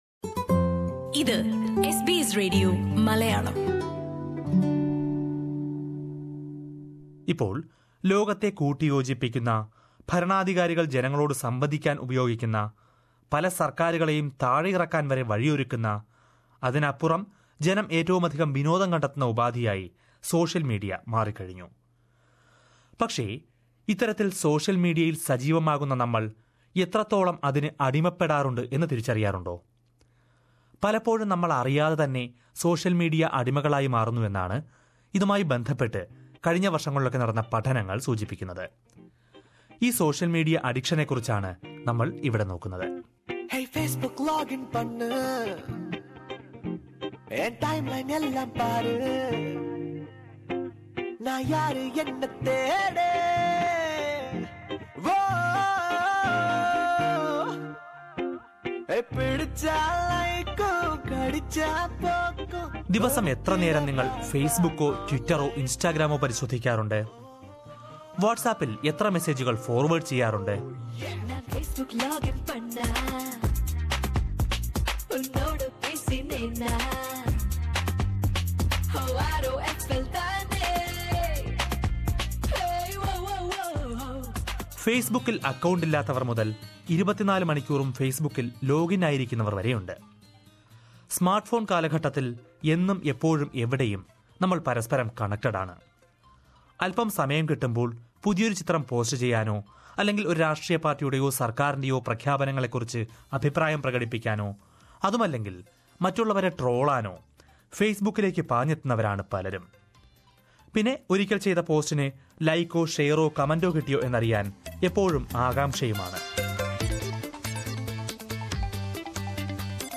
സോഷ്യല്‍ മീഡിയയുടെ അമിതോപയോഗത്തെയും അത് എങ്ങനെ നിയന്ത്രിക്കാമെന്നും മനശാസ്ത്രജ്ഞരുടെ ഭാഗത്തു നിന്നുള്ള വിലയിരുത്തല്‍ കേള്‍ക്കാം, ഈ റിപ്പോര്‍ട്ടില്‍...